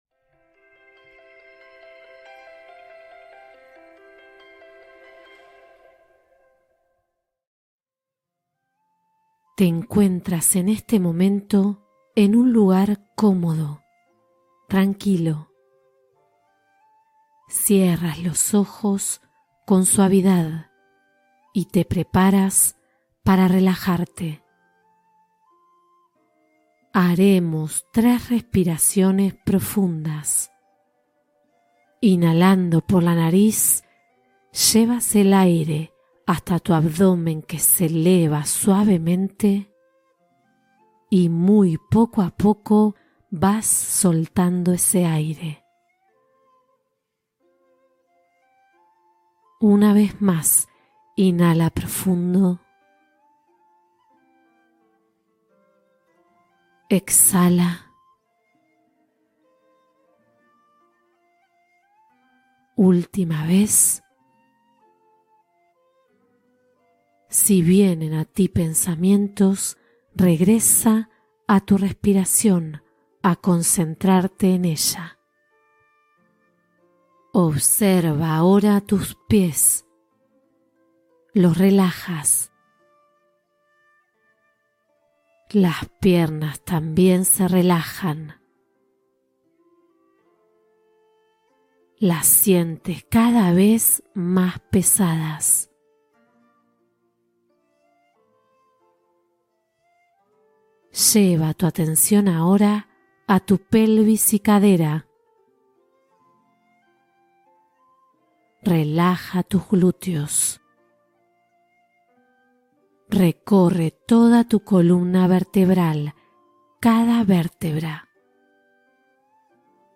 Enfoque y Deseo Consciente: Meditación para Activar la Atracción Interna